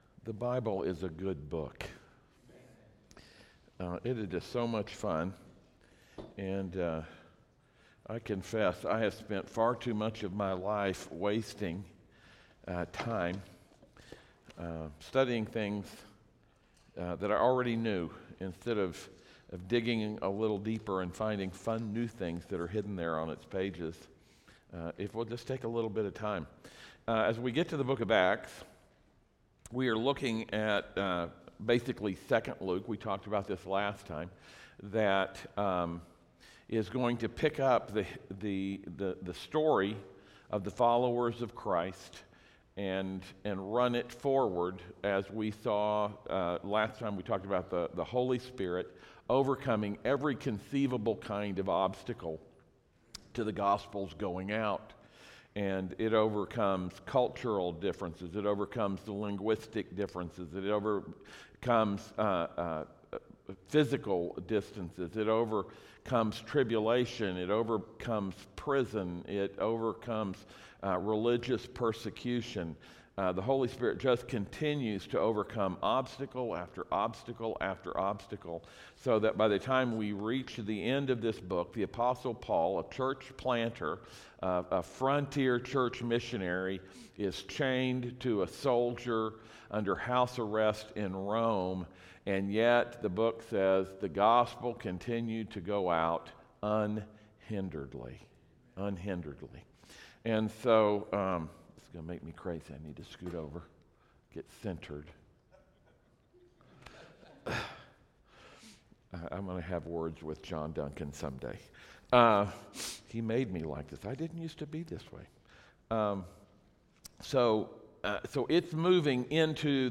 Acts 1:1-12 Service Type: audio sermons « Summer Baggage